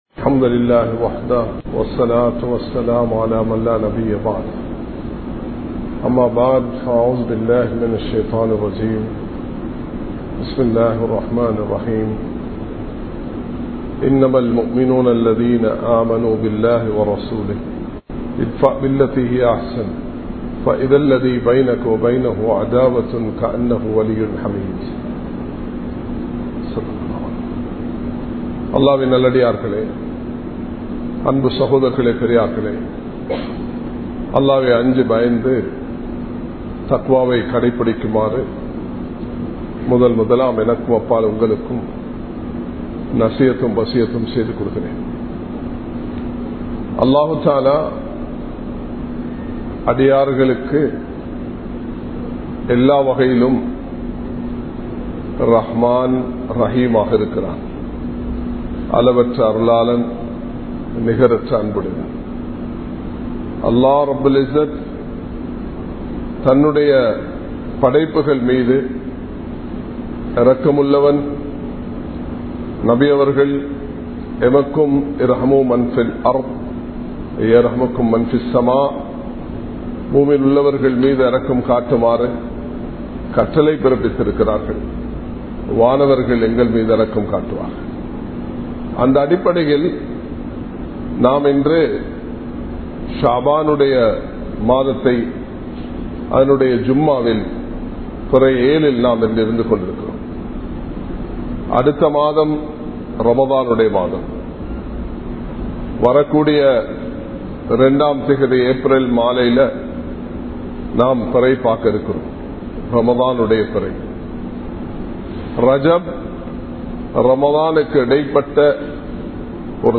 ஷஃபான் மாதத்தின் மகிமை | Audio Bayans | All Ceylon Muslim Youth Community | Addalaichenai
Kollupitty Jumua Masjith